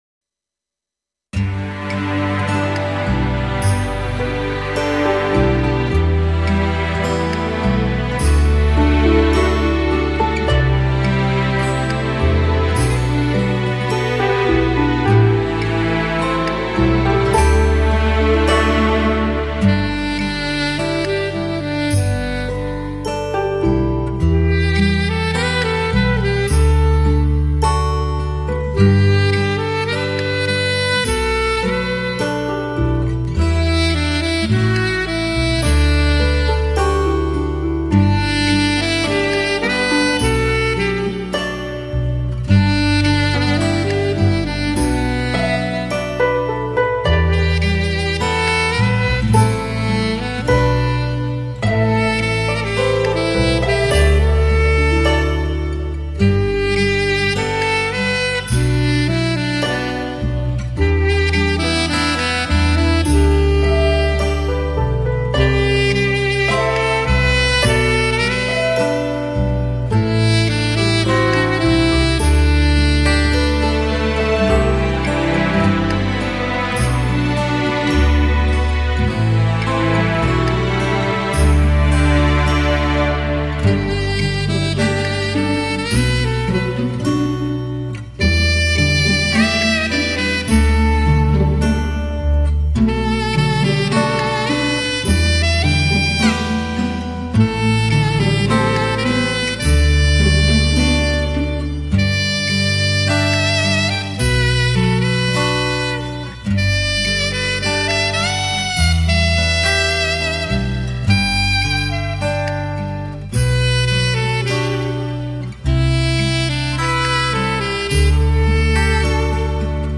乐与音的贯通融汇 管与弦的缠绵交织
东方神韵与西方器乐精彩对接撞击